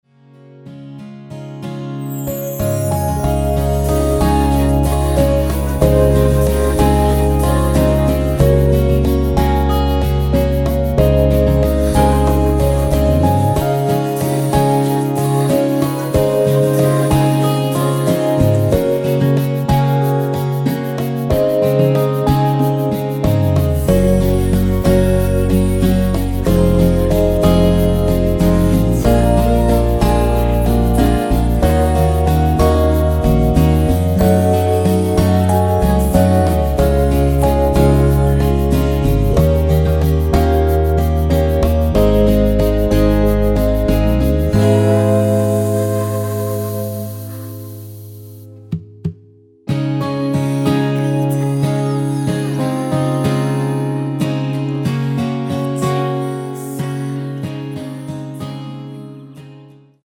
원키 코러스 포함된 MR입니다.
Db
앞부분30초, 뒷부분30초씩 편집해서 올려 드리고 있습니다.
중간에 음이 끈어지고 다시 나오는 이유는